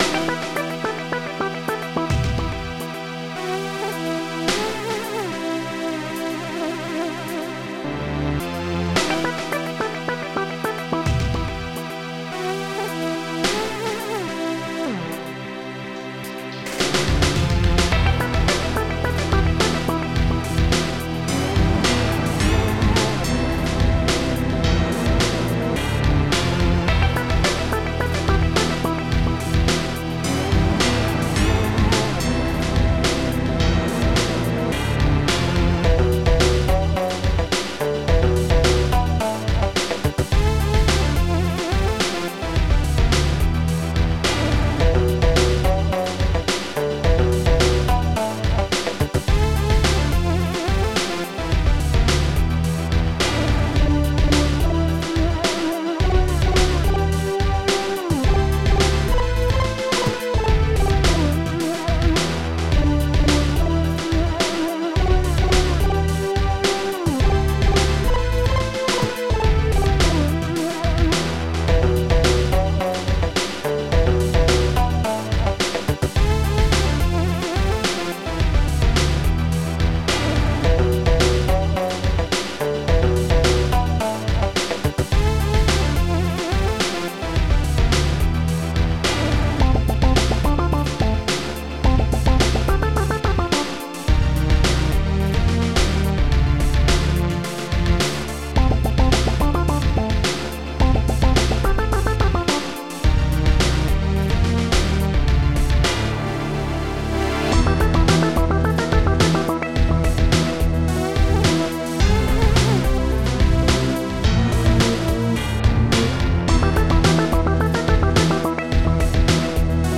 Protracker and family
ST-06:bd-hall
ST-08:snare.cocker
ST-01:flute